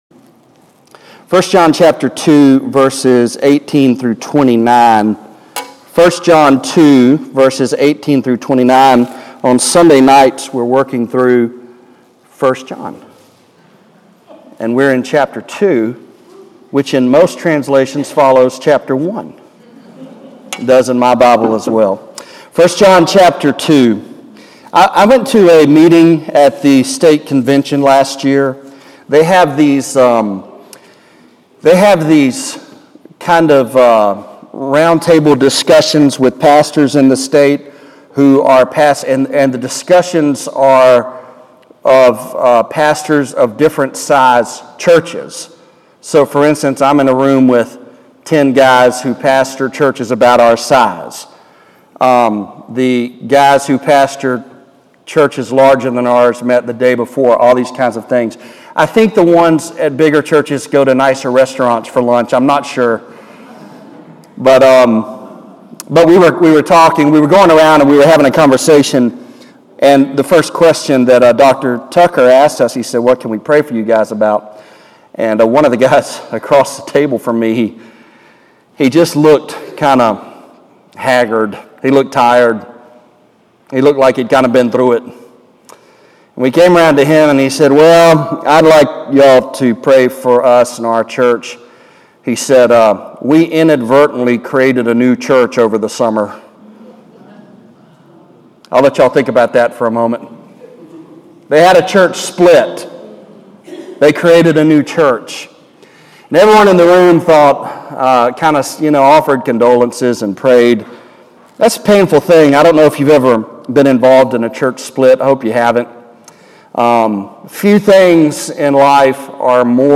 1 John 2:18-29 (Preached on September 18, 2016, at Central Baptist Church, North Little Rock, AR)